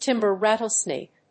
アクセントtímber ràttlesnake